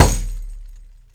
DOIRA 2A.WAV